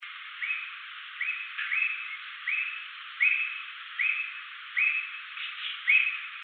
Common Squirrel Cuckoo (Piaya cayana)
Life Stage: Adult
Location or protected area: Reserva Privada y Ecolodge Surucuá
Condition: Wild
Certainty: Photographed, Recorded vocal